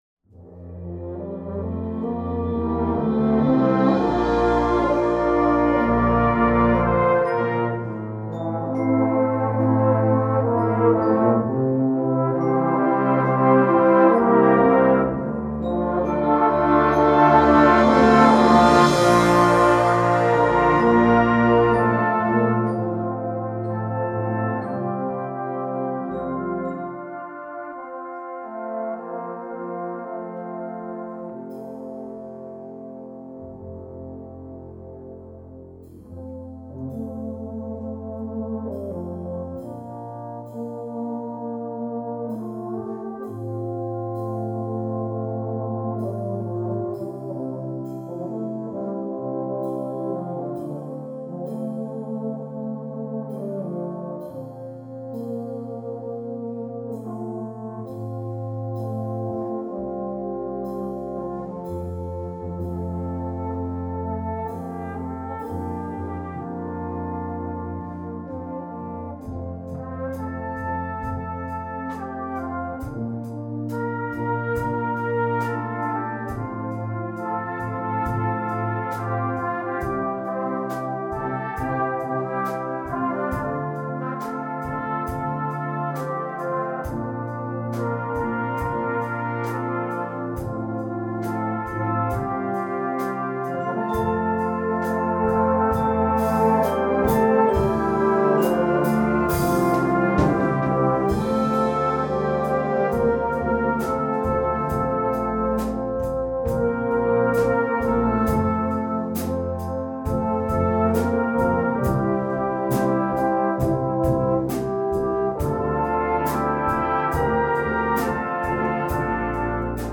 Composition Style: Song Arrangement